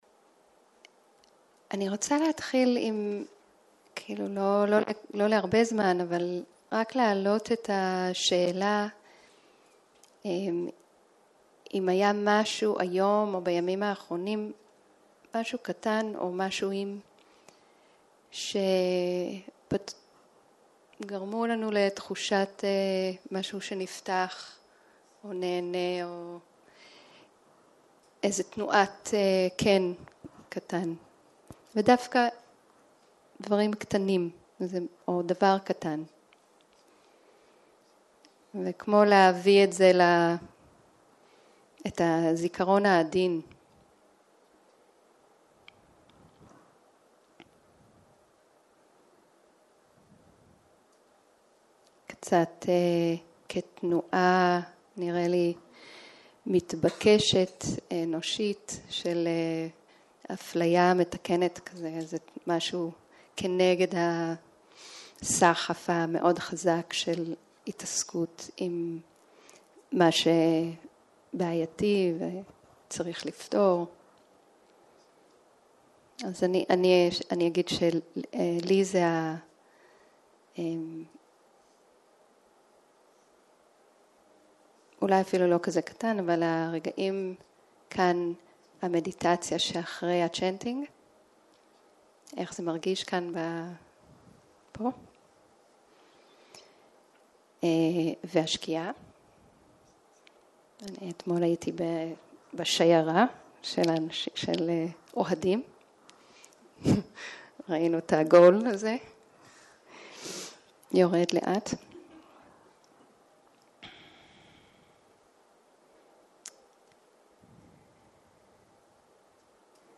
אחר הצהרים - שיחת דהרמה